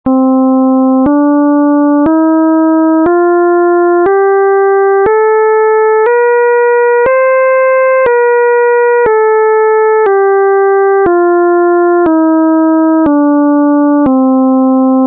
Βυζαντινή Μουσική - Κλίμακες
6. Διατονικὸ ἴσο (ὀμαλό) τοῦ Πτολεμαίου
Οἱ ἤχοι ἔχουν παραχθεῖ μὲ ὑπολογιστὴ μὲ ὑπέρθεση ἀρμονικῶν.
diatonic_ptolemaios_262.mp3